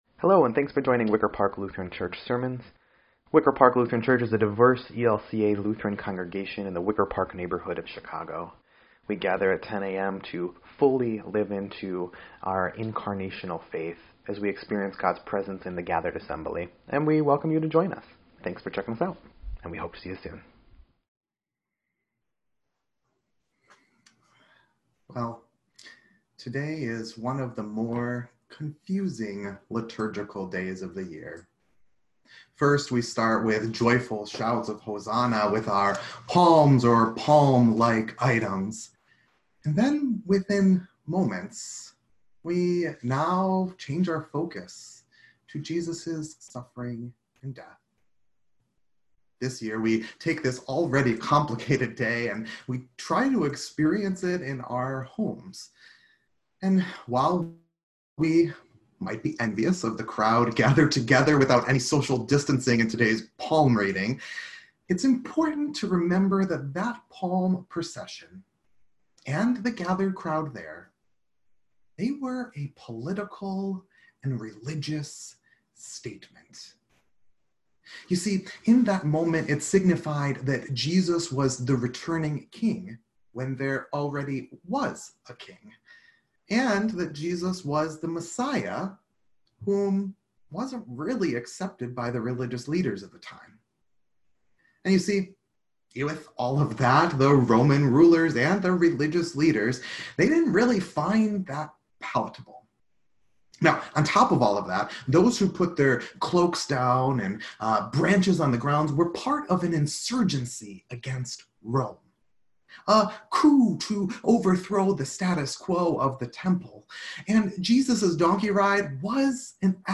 Listen to the audio recording of the Passion reading from today's service.